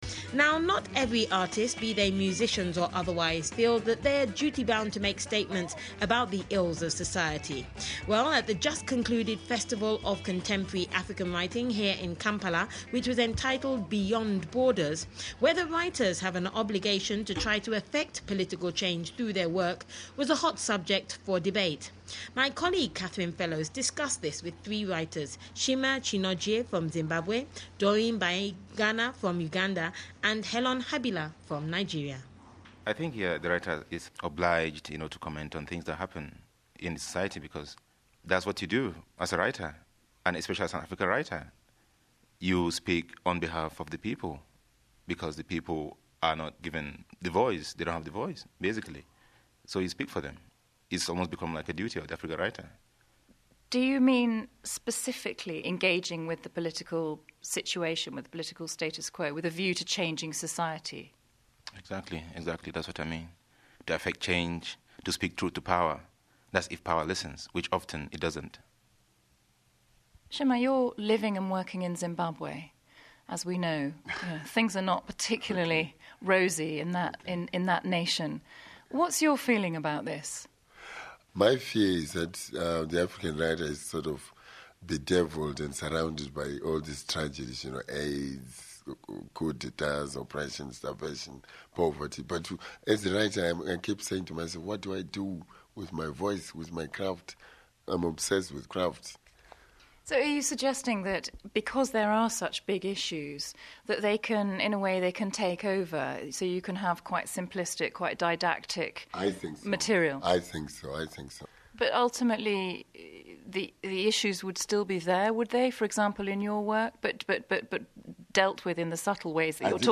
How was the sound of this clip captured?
The following interviews were broadcast on BBC Network Africa at the Weekend on BBC World Service to audiences across the African continent between October 2005 and March 2006.